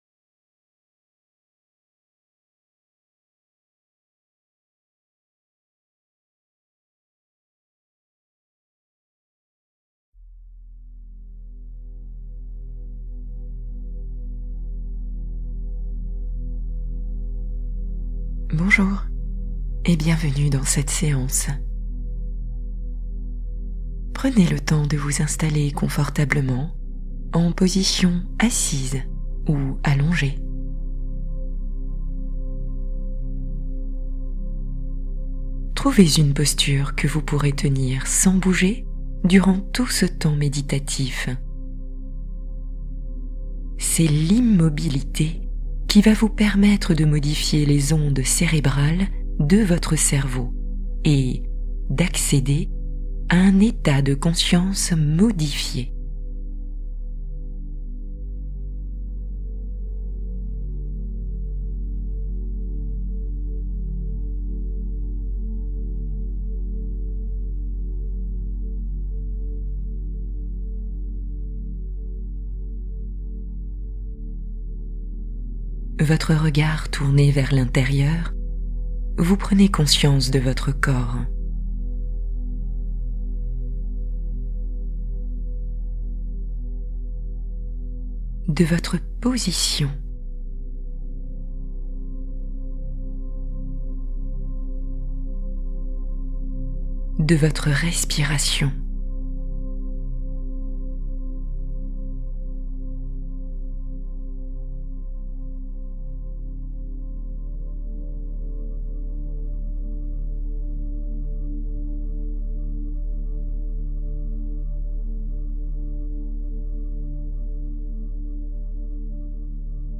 Balade anti-stress : méditation guidée en mouvement intérieur